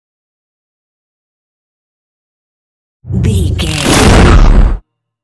Scifi whoosh to hit
Sound Effects
dark
futuristic
intense
tension